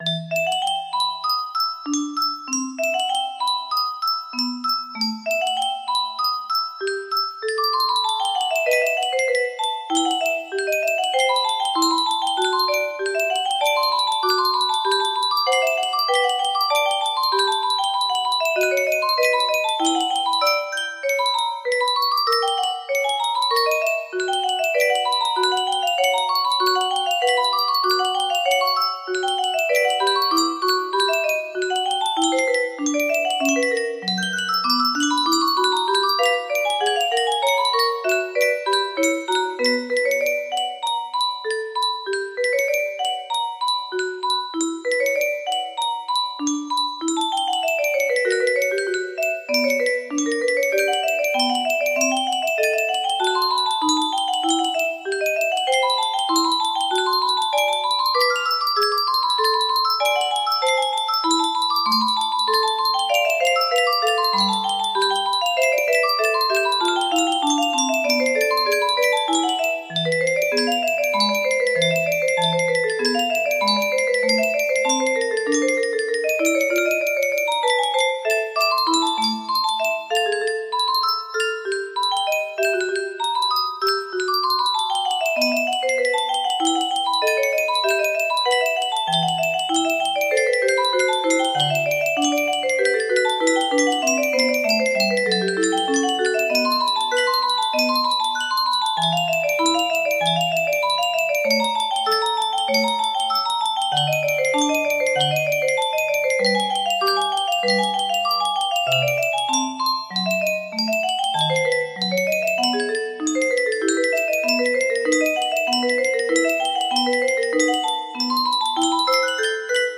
Bach - Lute suite n2 prelude music box melody
Full range 60